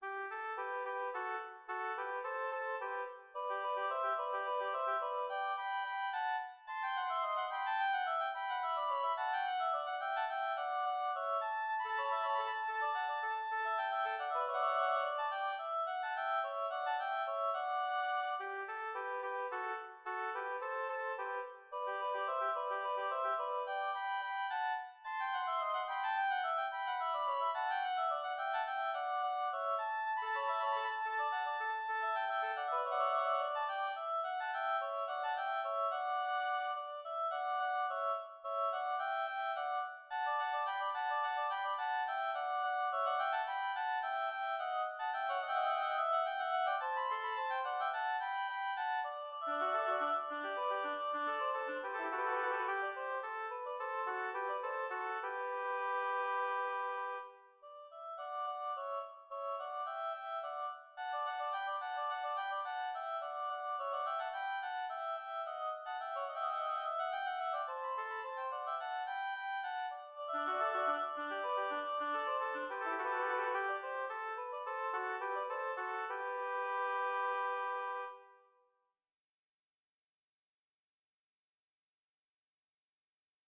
Intermediate oboe duet
double reed